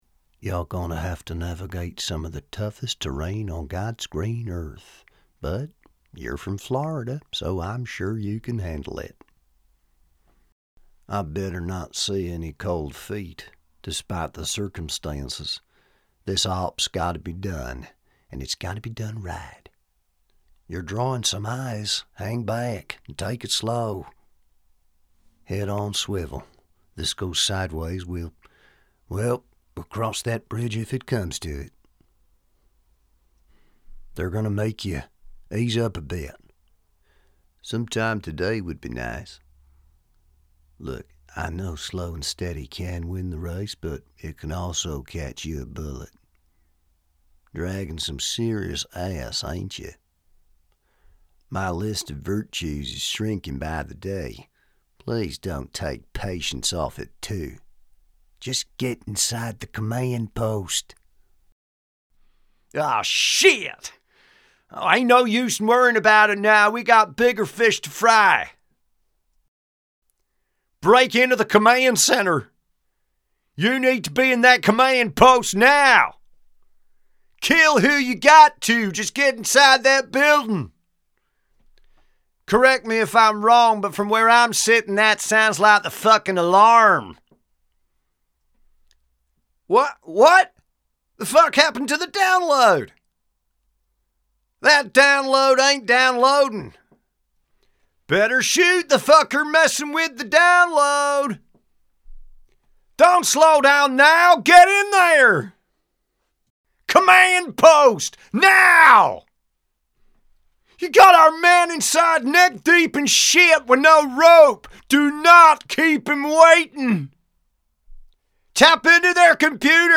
Texan-Game-Audition.mp3